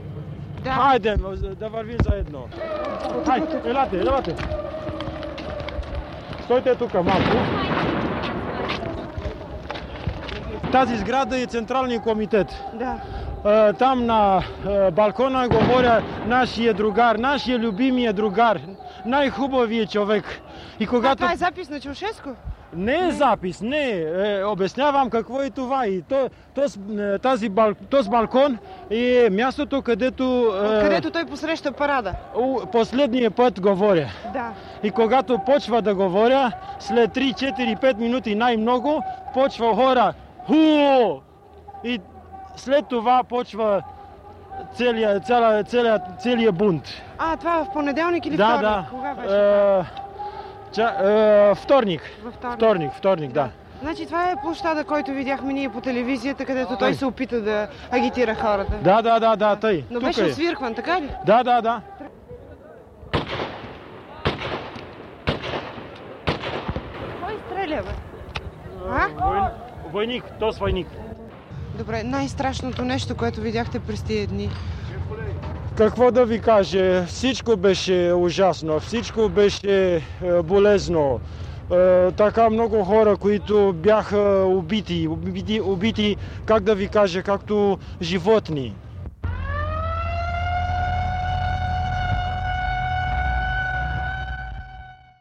разказват участници в събитията